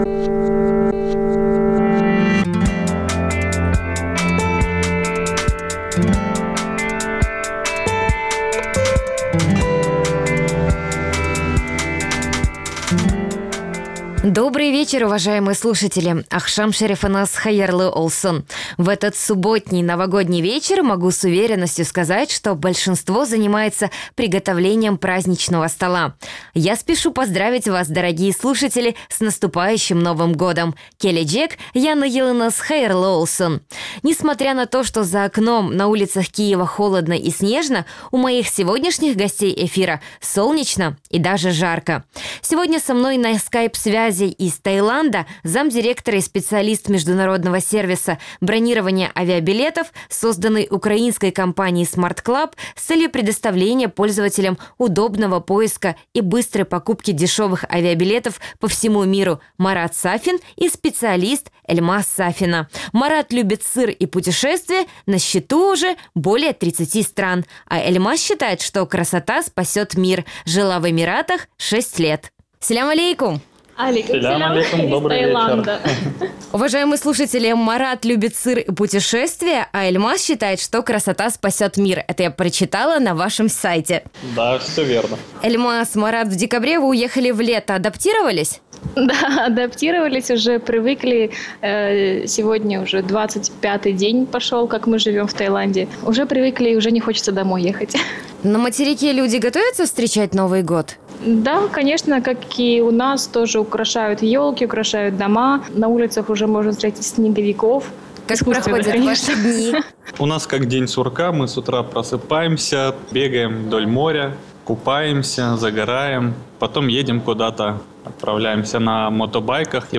Эфир можно слушать на сайте Крым.Реалии, а также на средних волнах на частоте 549 килогерц.